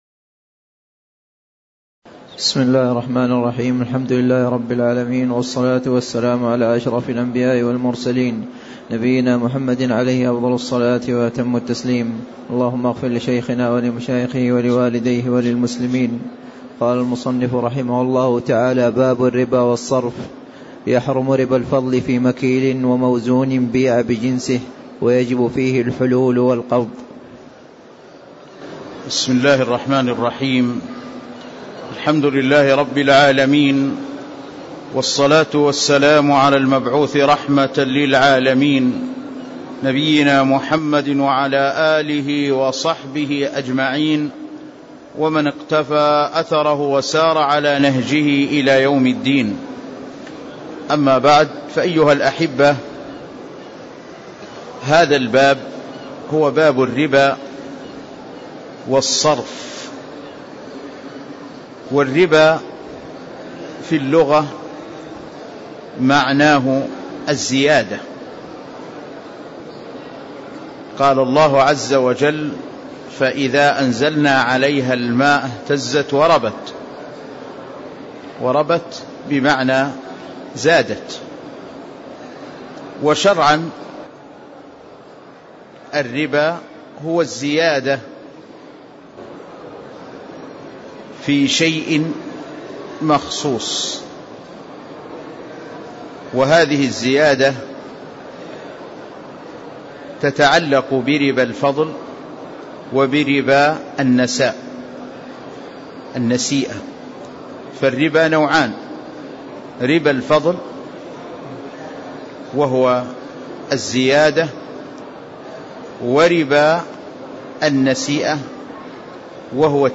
تاريخ النشر ١٦ رجب ١٤٣٦ هـ المكان: المسجد النبوي الشيخ